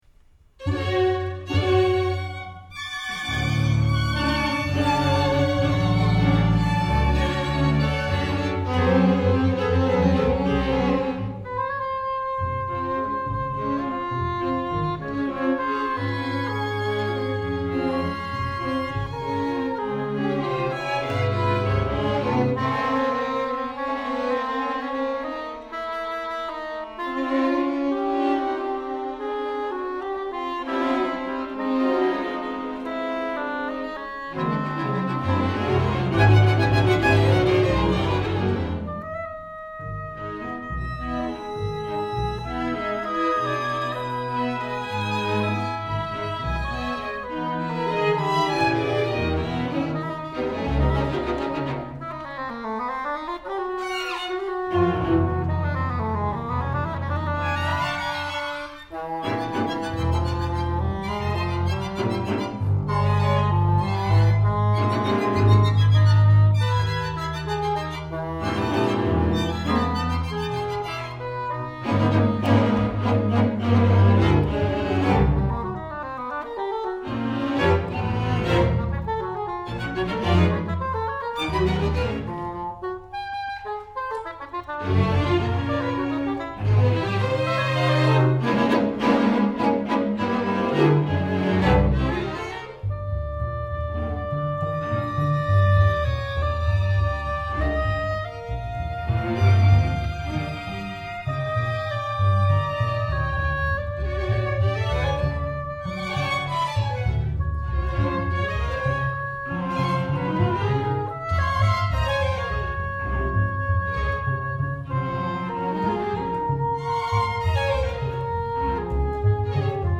• Thriller (00:00);
• Drama (00:00);
• Horror (00:00)